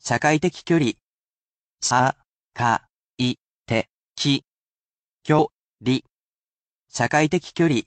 I read these aloud for you, as well, but you can use this as a useful opportunity to practise your reading skills.